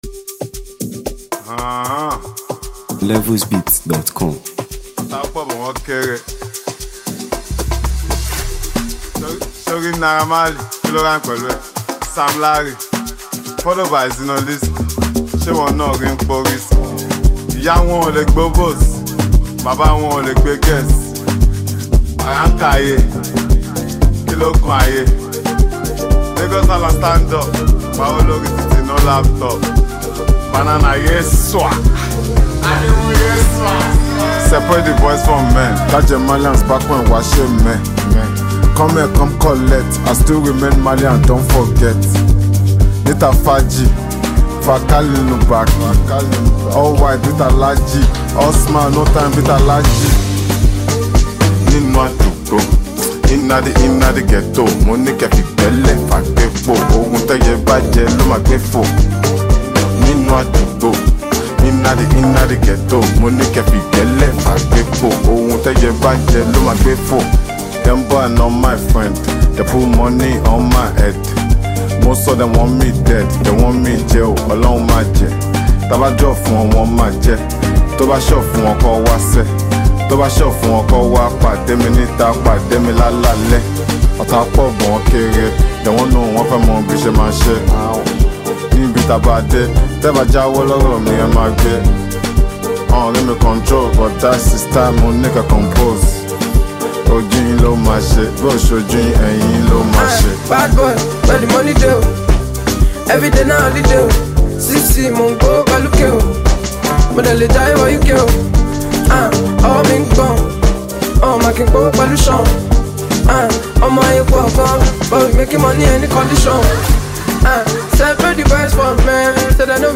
energetic jam
is a street-inspired banger packed with infectious vibes
lively and trendy sounds